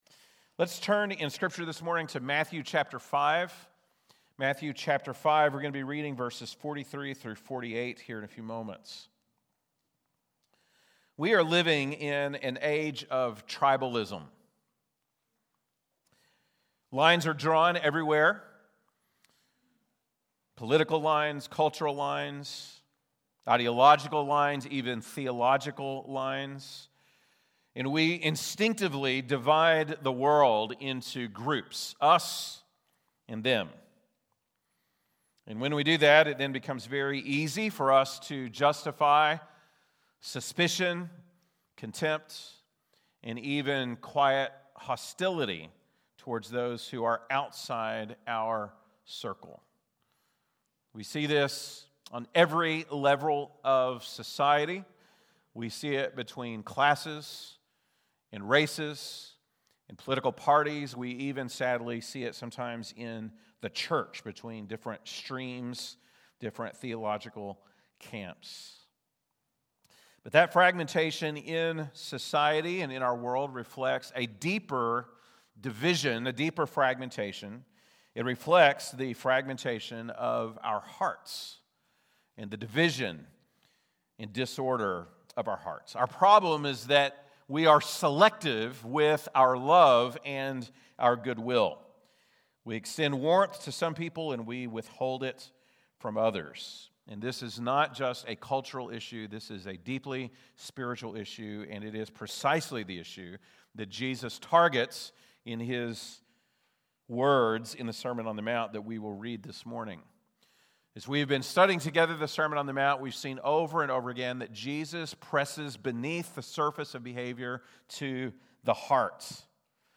February 15, 2026 (Sunday Morning)